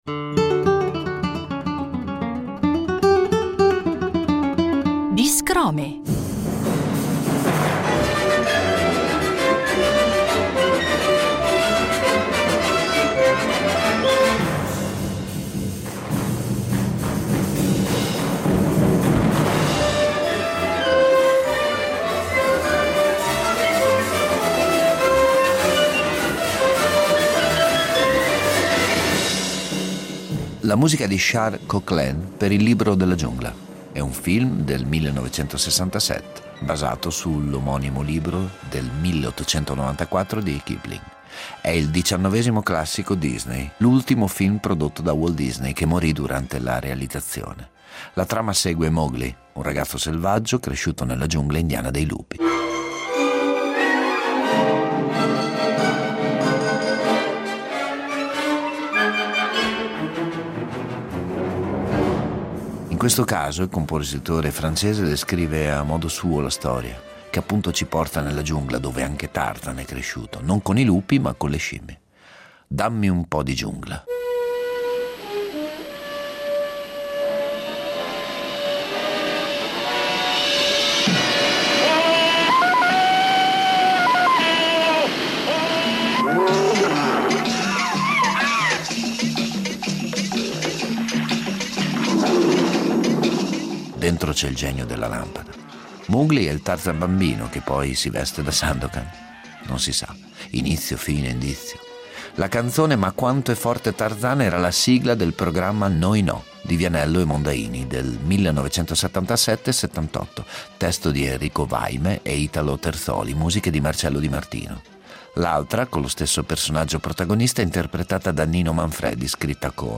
Un viaggio sonoro sul filo della memoria, dal dopoguerra al Boom economico, dal bianco e nero al colore, dall’avanspettacolo alle sigle tv, in pausa carosello e pubblicità, seguendo un flusso emotivo spazio temporale che ci riporta dentro la nostra bolla amniotica.